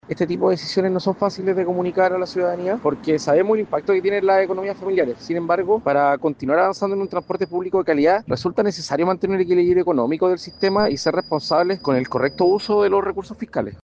Por su parte, Jean Pierre Ugarte, jefe regional de La Secretaría Regional Ministerial del Ministerio de Transportes y Telecomunicaciones en Valparaíso, justificó el alza indicando, que es necesaria para sostener el equilibrio fiscal y financiero del sistema